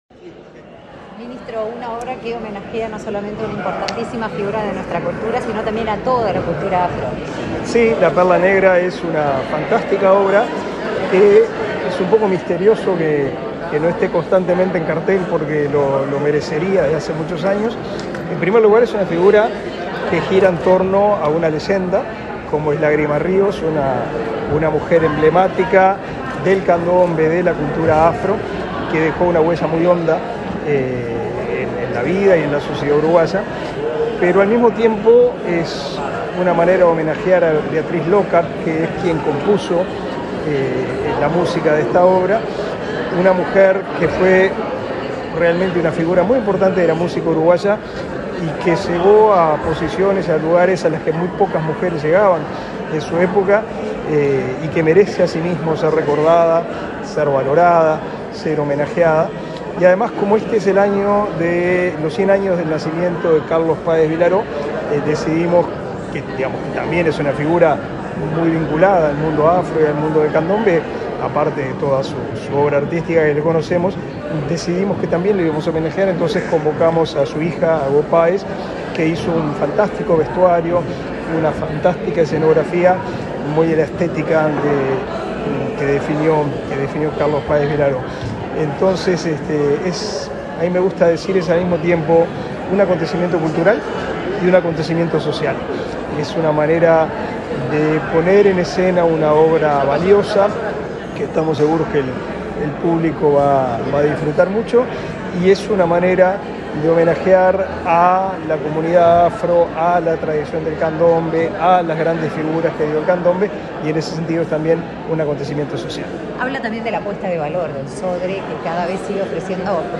Declaraciones a la prensa del ministro de Educación y Cultura, Pablo da Silveira